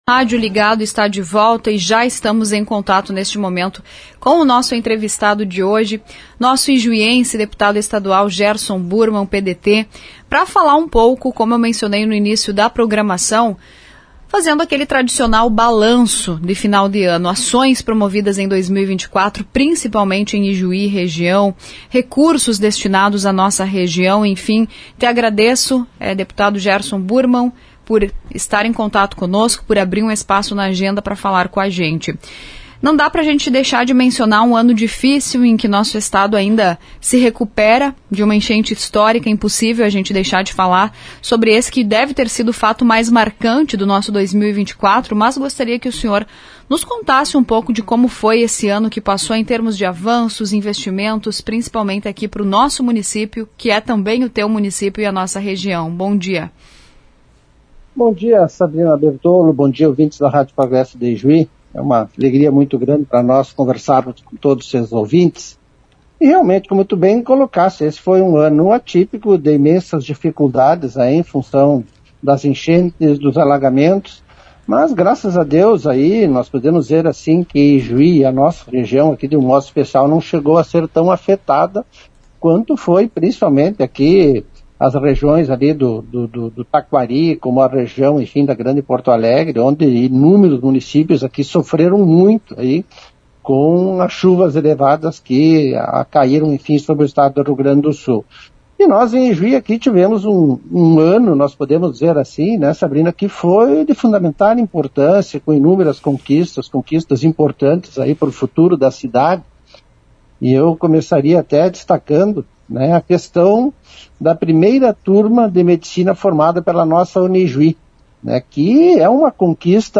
O Deputado Estadual pelo PDT, ijuiense Gerson Burmann, falou em entrevista à Rádio Progresso nesta manhã (30), sobre as principais conquistas alcançadas ao longo de 2024 para Ijuí e região. Na área da saúde, mencionou a conclusão da primeira turma do curso de medicina da Unijuí como um dos principais avanços.